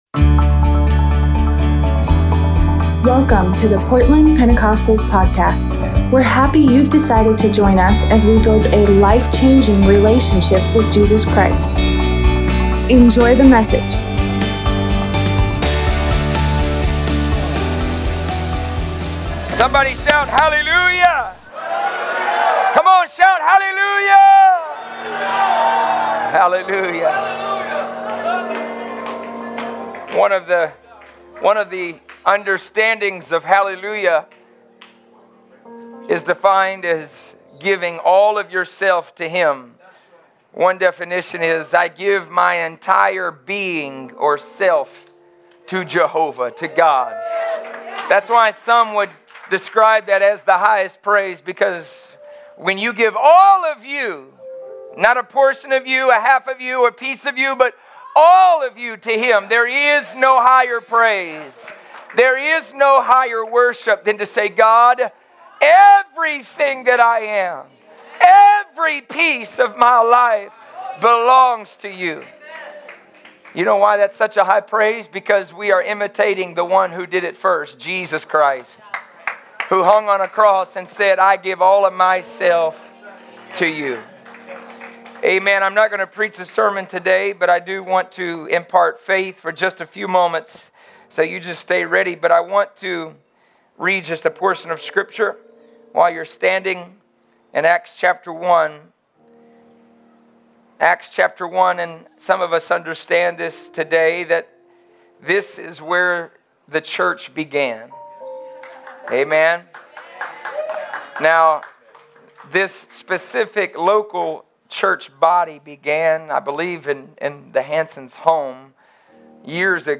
Sunday morning revival service